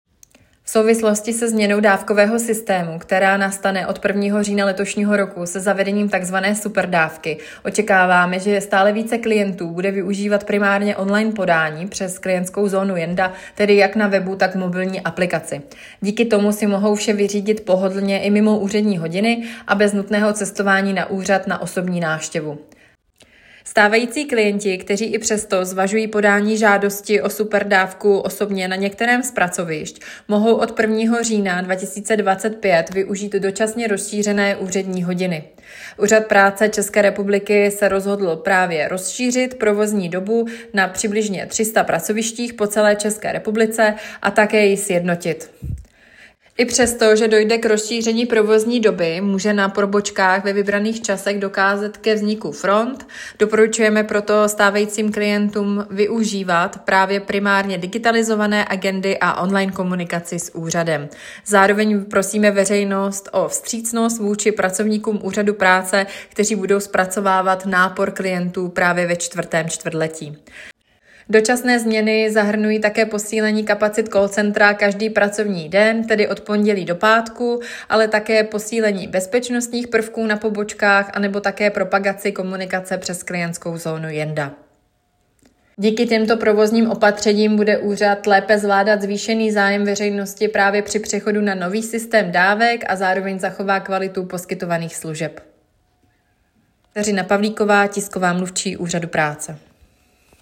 Komentář